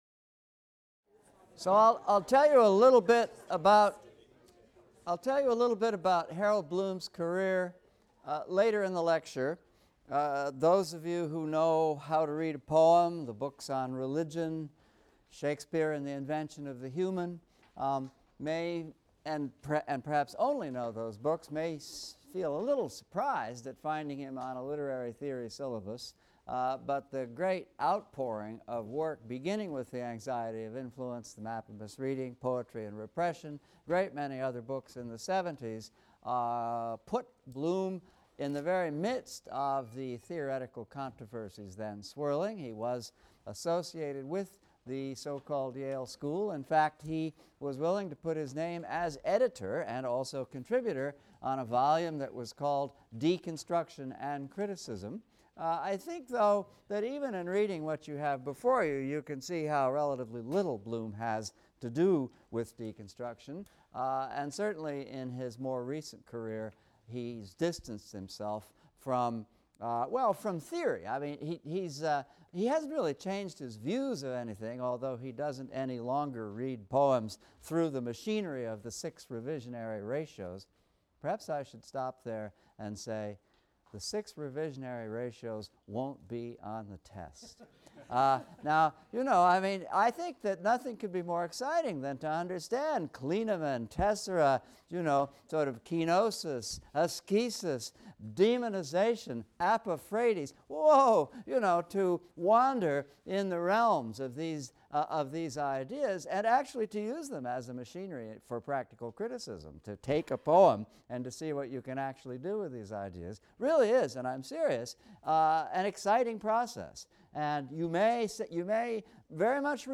ENGL 300 - Lecture 14 - Influence | Open Yale Courses